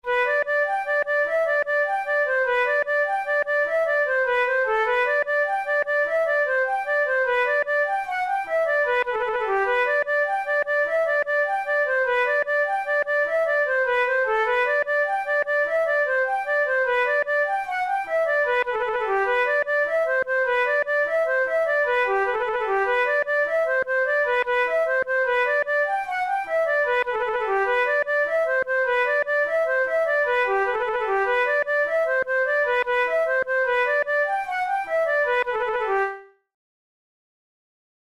Jigs, Traditional/Folk
Traditional Irish jig